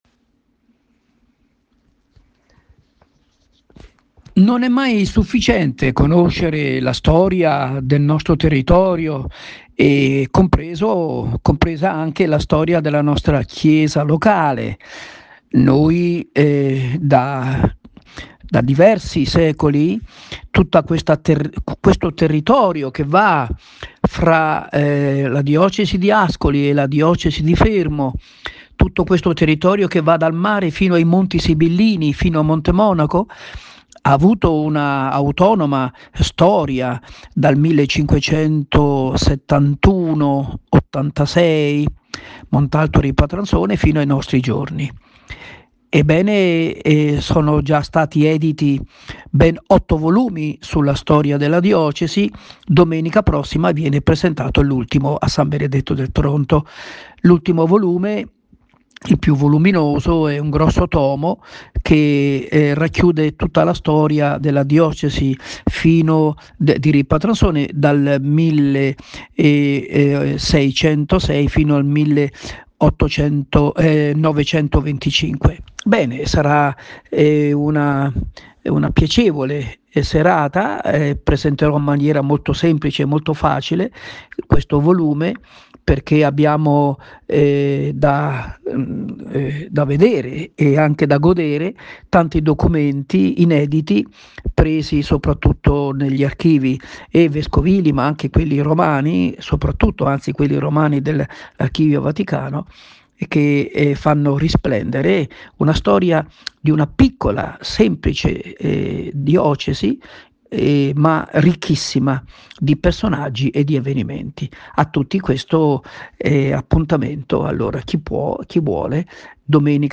L’invito audio